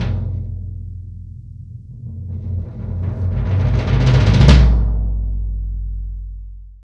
Index of /90_sSampleCDs/AKAI S6000 CD-ROM - Volume 3/Kick/GONG_BASS